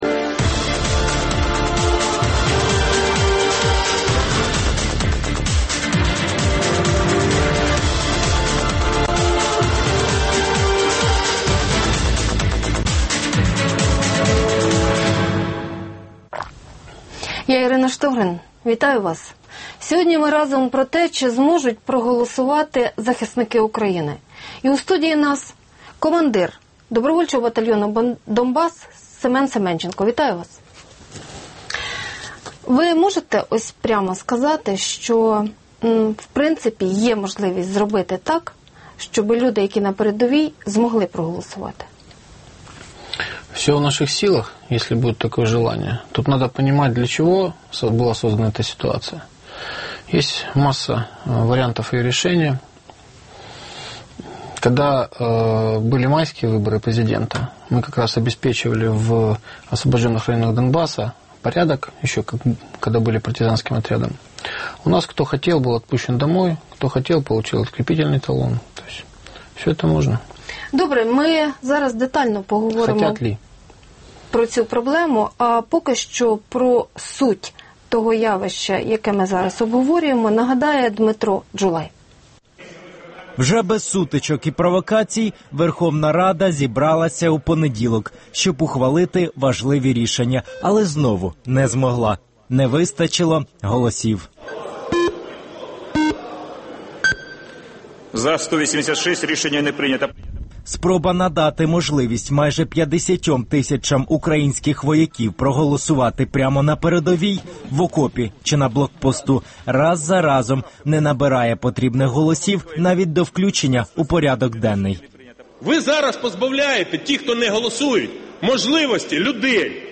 Гість: командир добровольчого батальйону "Донбас" Семен Семенченко.